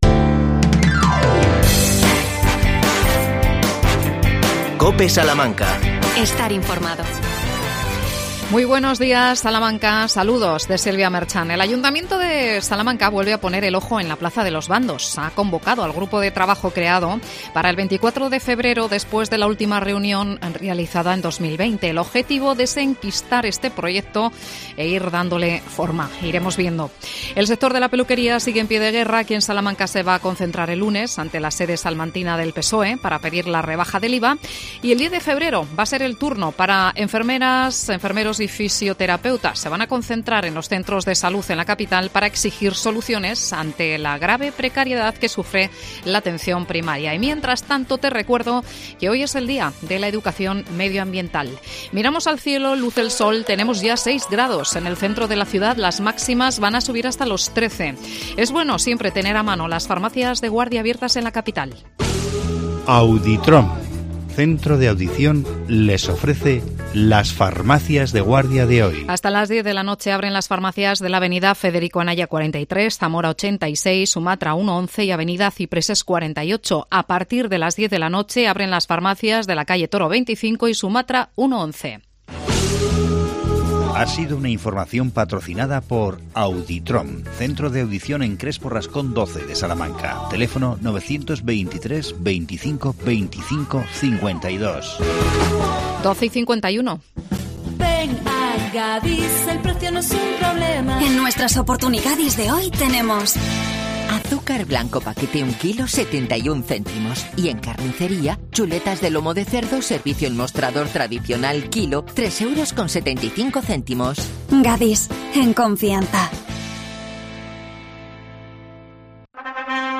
Arrancan las visitas escolares al Museo Chacinero de Guijuelo. Nos acercamos a él con la concejala de Turismo Sandra Méndez.